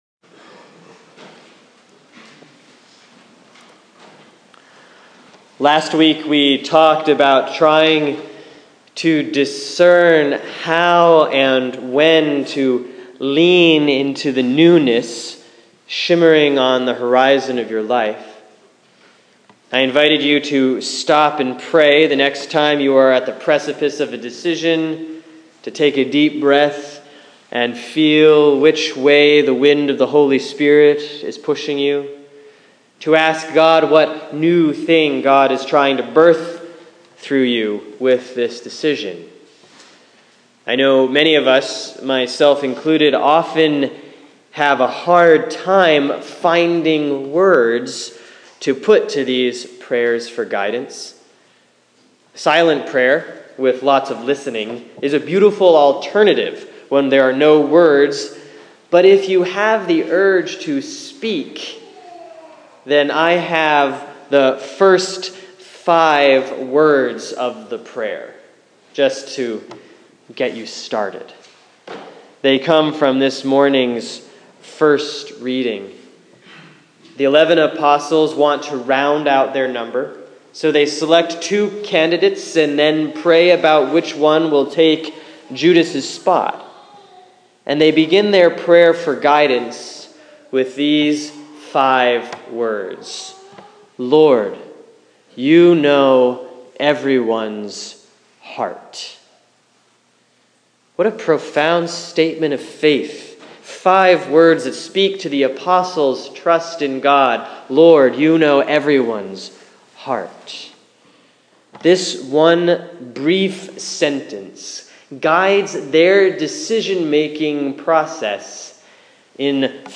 Sermon for Sunday, May 17, 2015 || Easter 7B || Acts 1:15-17, 21-26